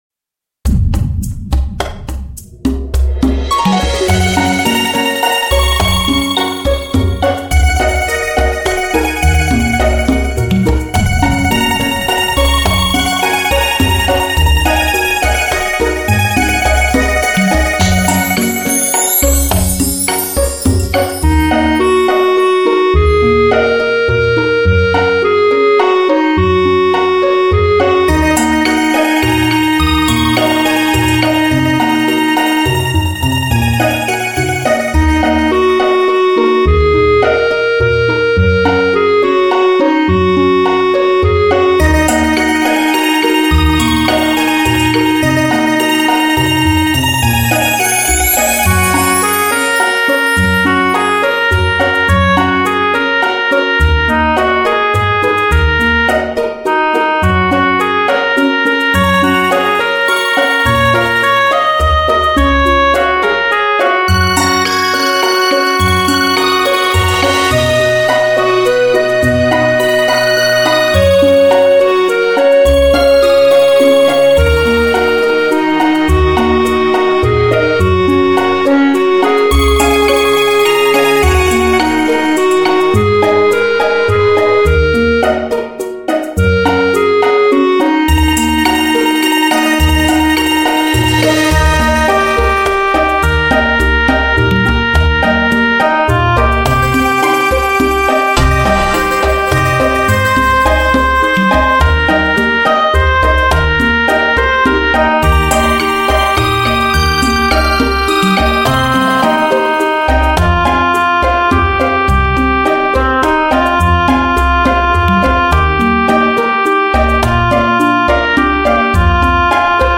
专辑格式：DTS-CD-5.1声道
纯净晶莹的乐曲 一如似水的岁月,